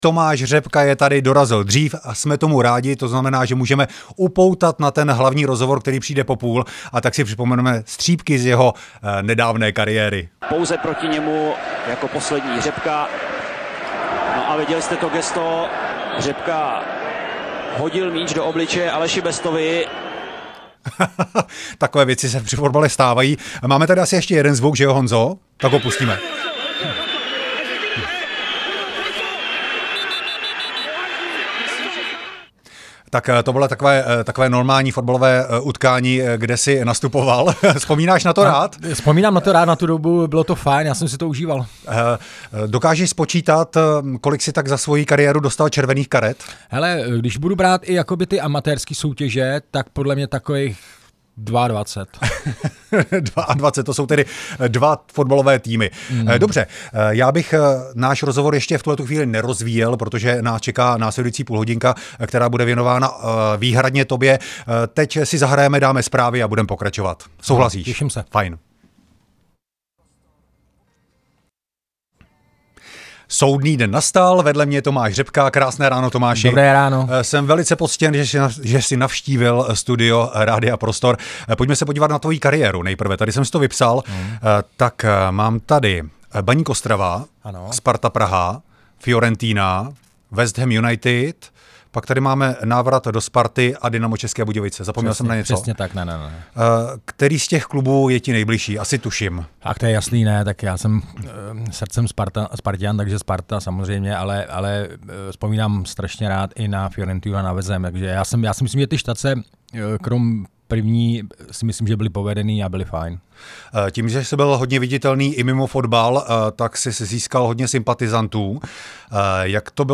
Rozhovor s fotbalistou Tomášem Řepkou